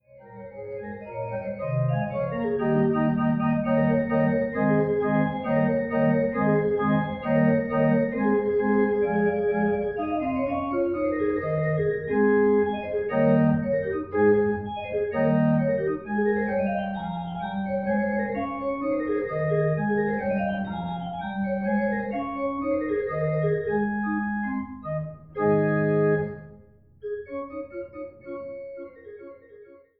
Cembalo vermutlich sächsischer Herkunft, um 1700